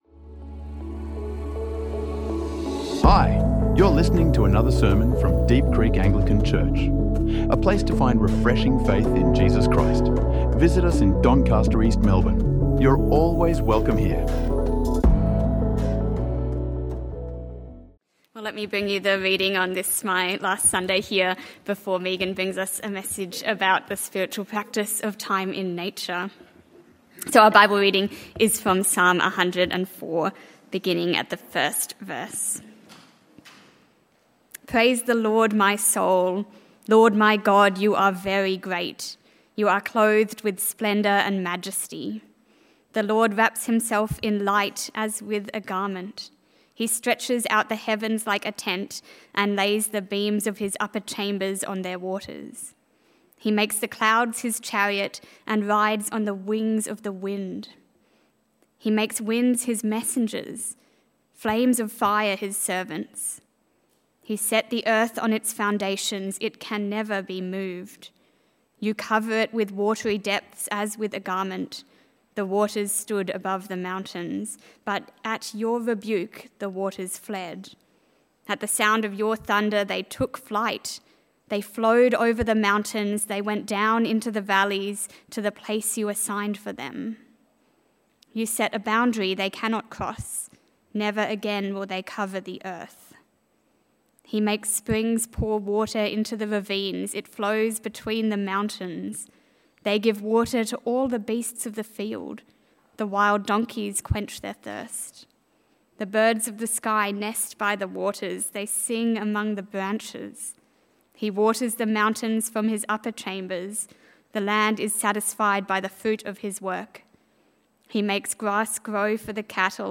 Explore Psalm 104, time in nature and gratitude as spiritual practices that form us to trust God's rhythms, not busyness, in this sermon from Deep Creek.